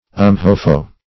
Search Result for " umhofo" : The Collaborative International Dictionary of English v.0.48: umhofo \um*ho"fo\ ([u^]m*h[=o]"f[-o]), n. (Zool.)